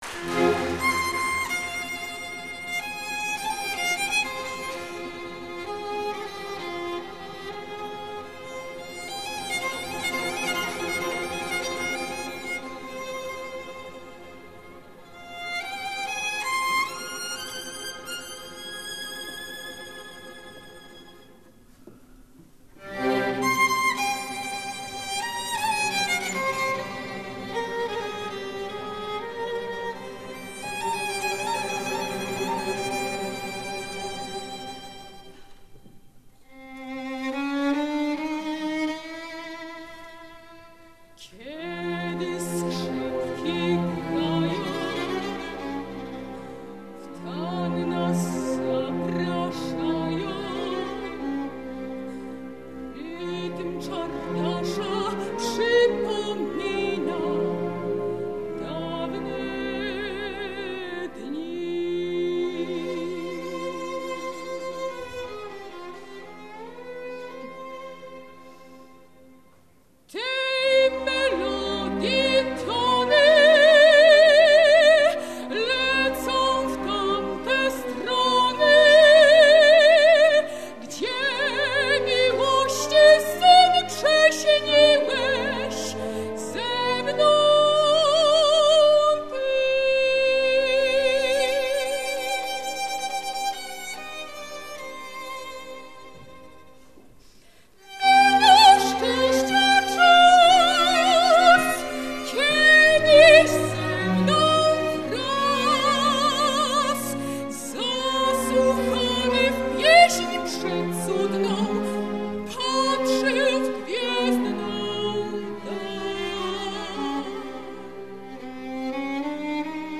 z operetki